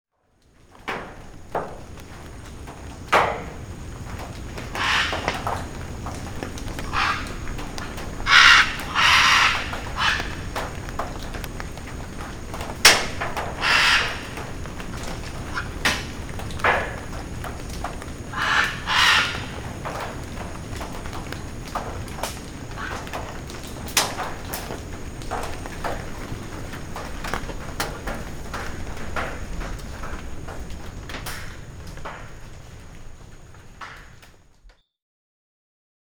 „Sempervirent“ is a stereo edit of a series of ’sequence-shots‘ where the quadraphonic microphone setup records all that passes by.
06 breakfast on tin roofs – scarlet macaw – 2’17
scarlet_macaw_breakfast_excerpt.mp3